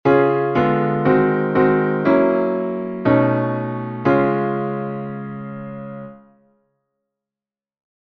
Key written in: D♭ Major
How many parts: 4
Type: Barbershop
All Parts mix: